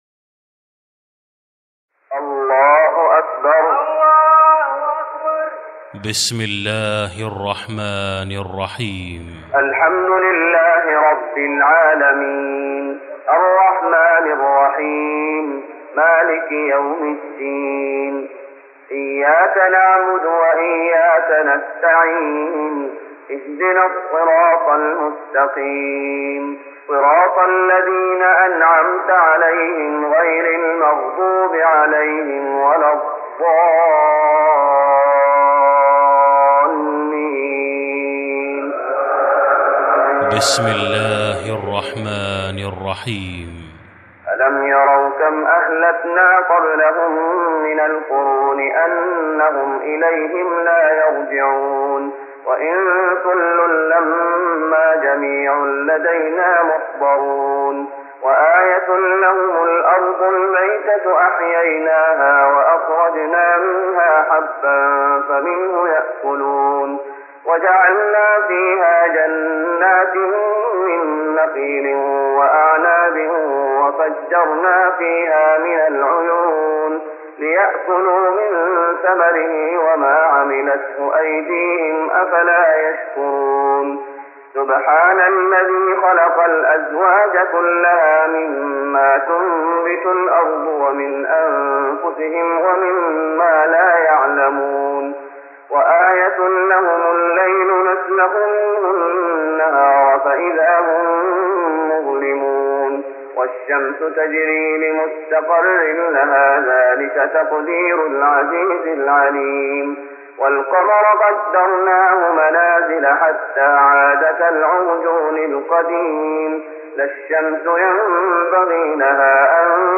تراويح رمضان 1414هـ من سور يس (31-83) Taraweeh Ramadan 1414H from Surah Yaseen > تراويح الشيخ محمد أيوب بالنبوي 1414 🕌 > التراويح - تلاوات الحرمين